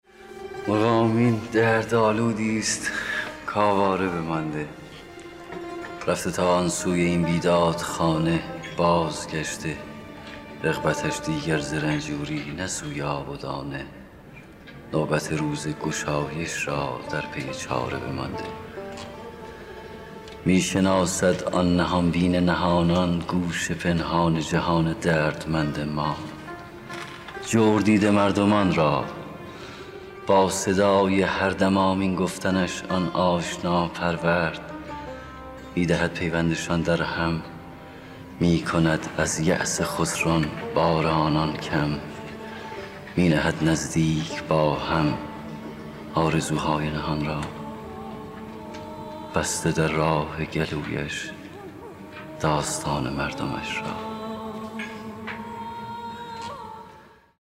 دانلود دکلمه مرغ آمین با صدای مصطفی زمانی
گوینده :   [مصطفی زمانی]